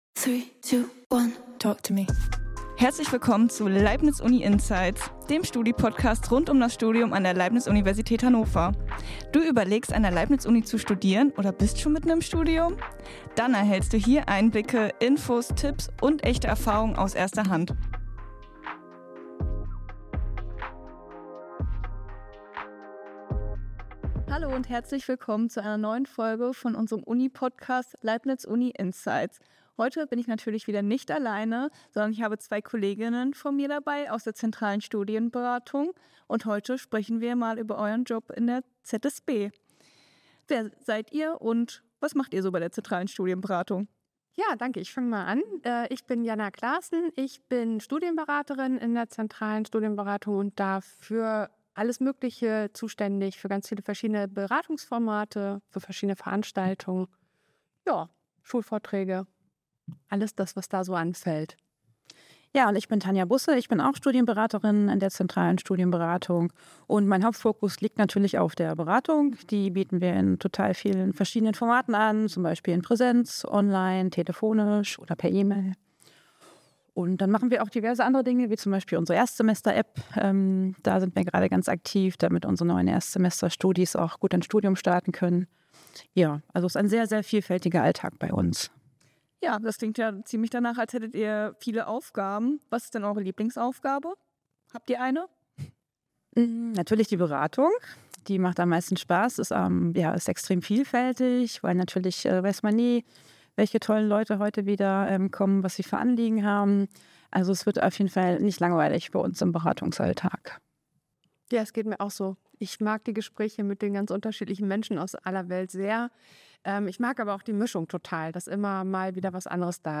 Persönliche Geschichten aus der Beratung, lustige Anekdoten und die eigenen Studienwege der beiden Beraterinnen zeigen, wie vielfältig Studienentscheidungen sein können.